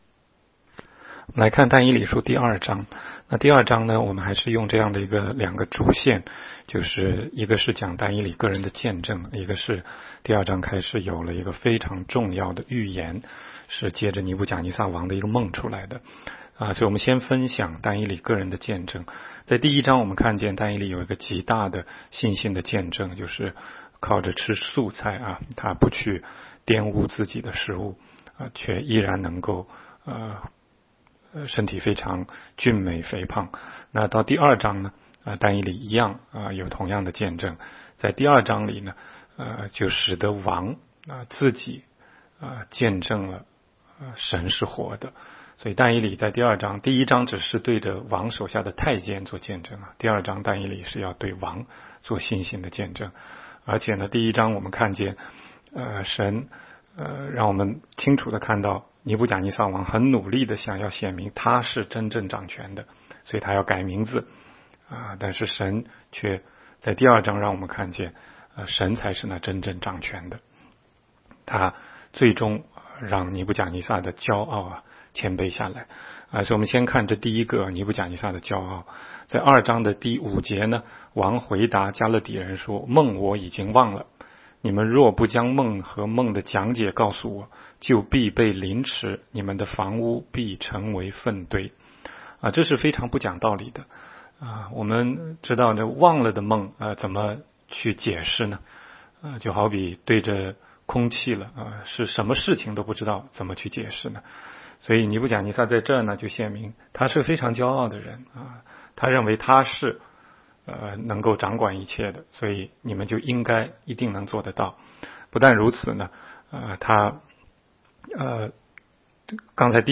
16街讲道录音 - 每日读经 -《但以理书》2章